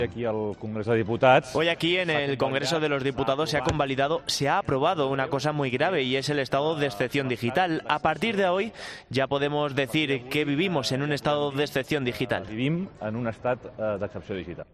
Declaraciones de Jordi Puigneró